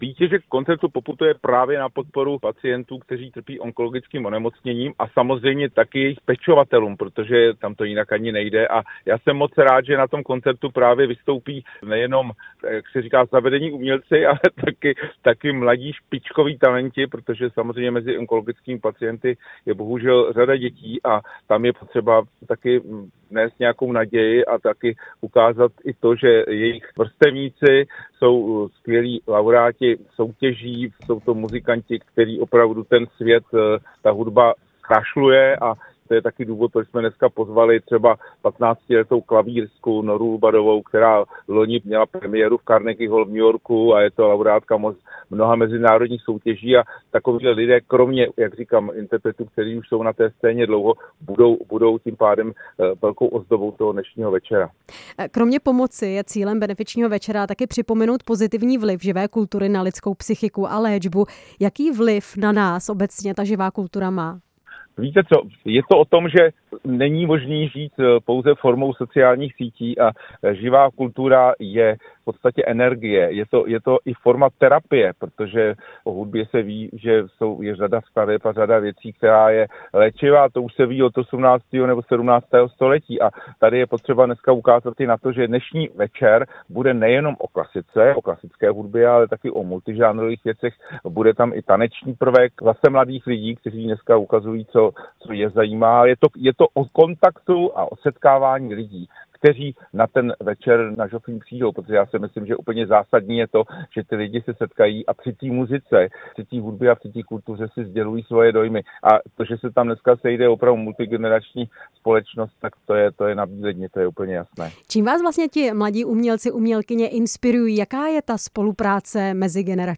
Kam poputuje výtěžek koncertu a na co dále koncert upozorní? Jaroslav Svěcený byl hostem vysílání Radia Prostor.